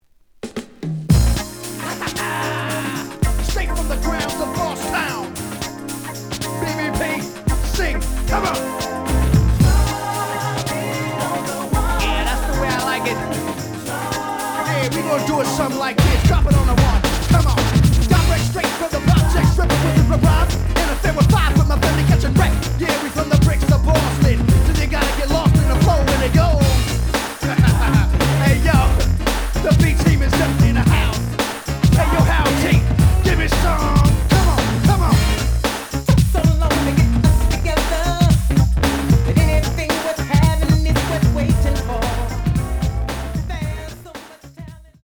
The audio sample is recorded from the actual item.
●Genre: Hip Hop / R&B
Slight edge warp. But doesn't affect playing. Plays good.)